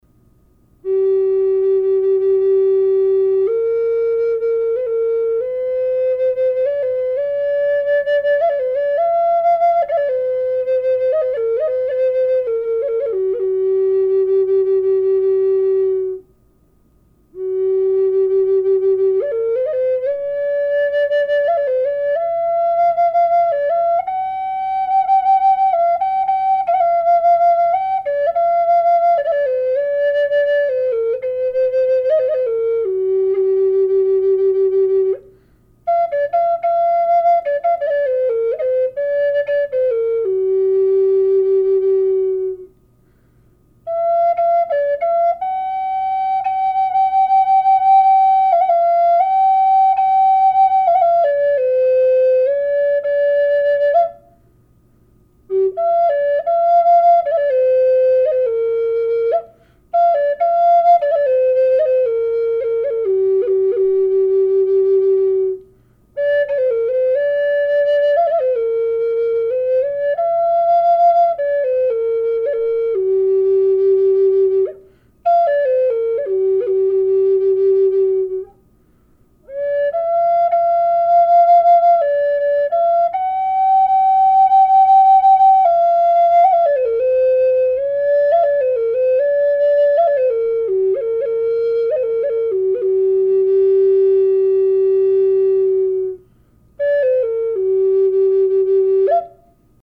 Handcrafted from Kachina Peaks Aspen wood and Sedona Red Manzanita,
Listen to G A432Hz frequency
No effect added
vortex-432-g-minor.mp3